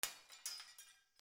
04 鏡を割る
/ H｜バトル・武器・破壊 / H-45 ｜ガラス